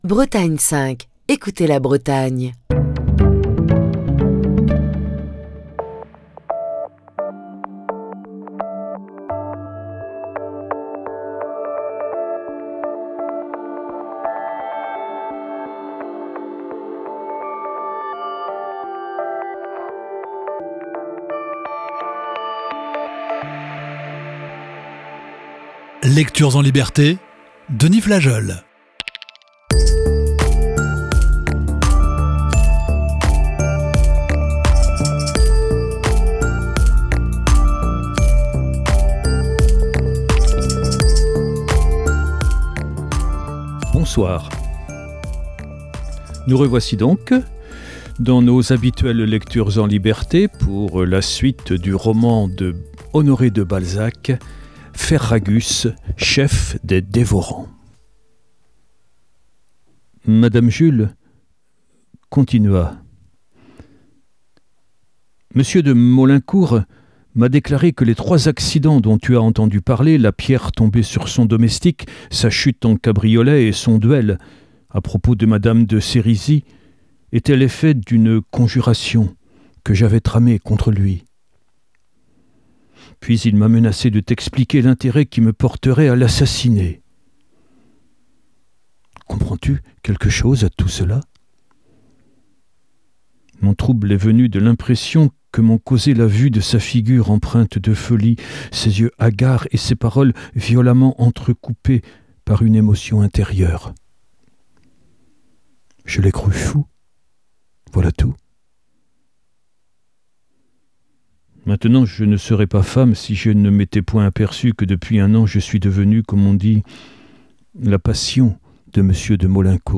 Émission du 27 mai 2024.